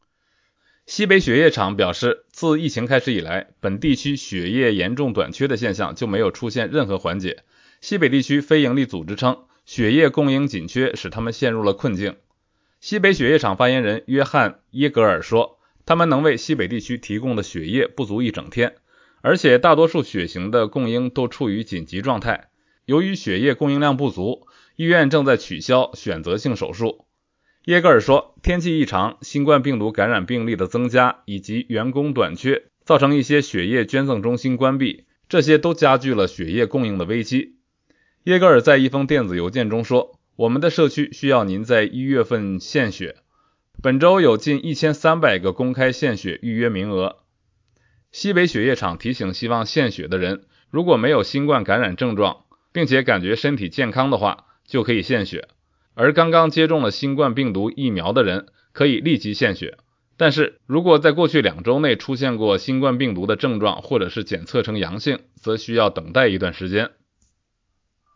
每日新聞